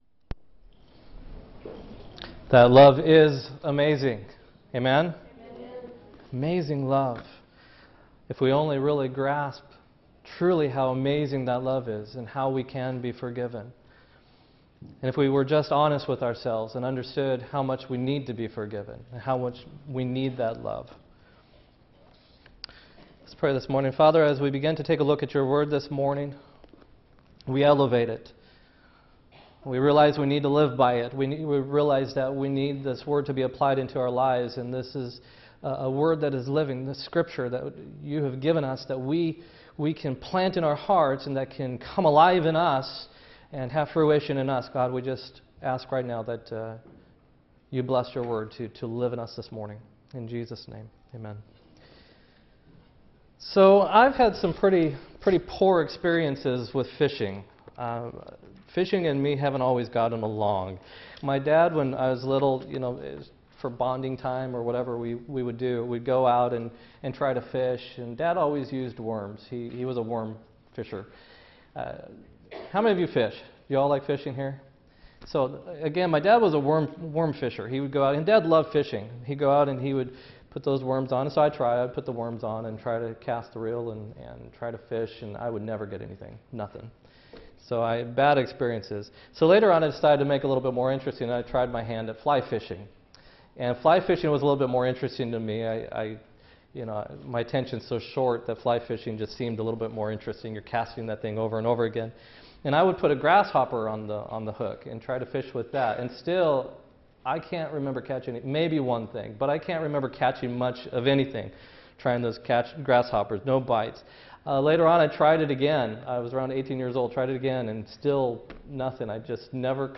10-8-16-sermon